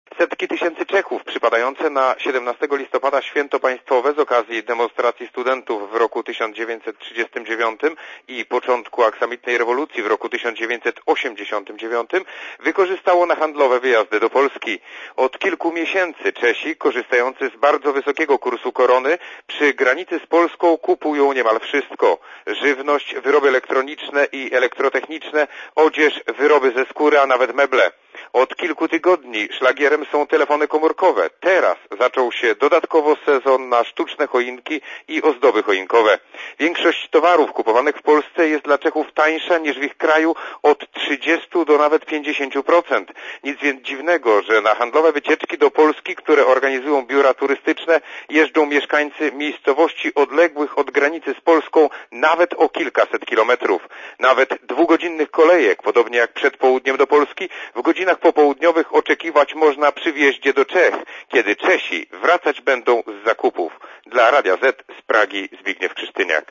Posłuchaj relacji korespondenta Radia Zet (259 KB)